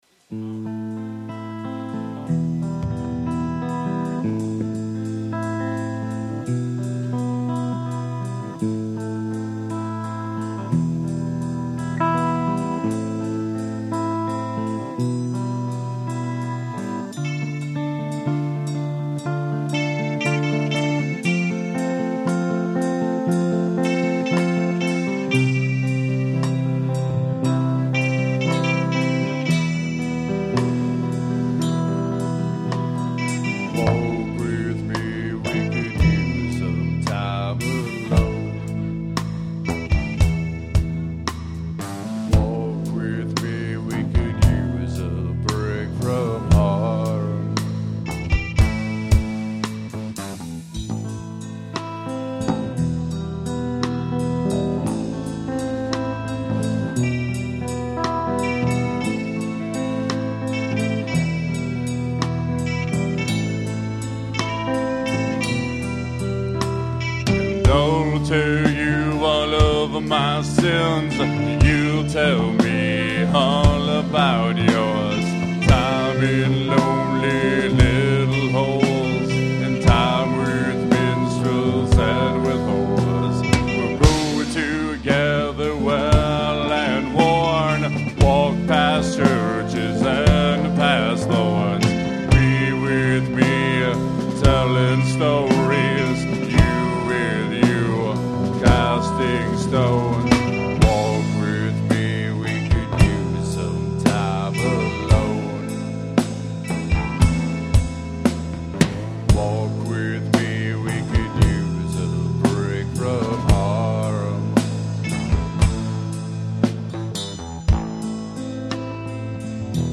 Live at The Red Sea